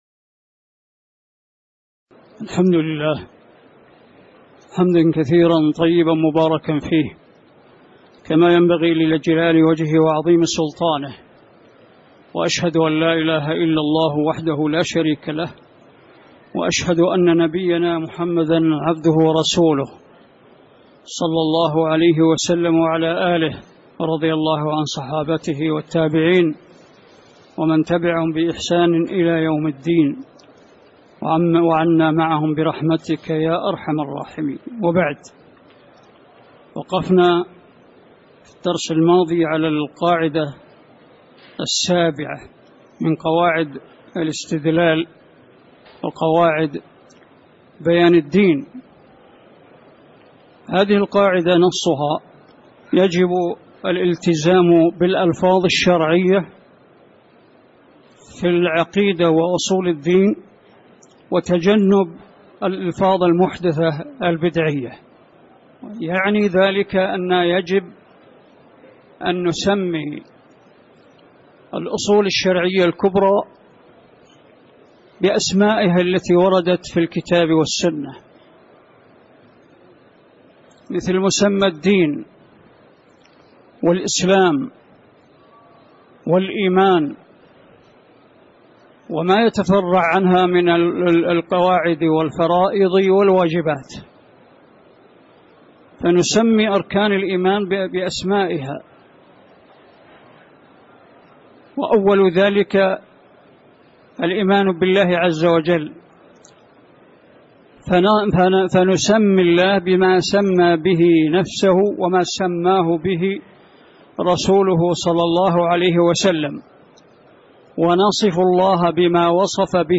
تاريخ النشر ١٦ ذو القعدة ١٤٣٨ هـ المكان: المسجد النبوي الشيخ: ناصر العقل ناصر العقل من قوله: القاعده السابعة من قواعد الإستدلال (02)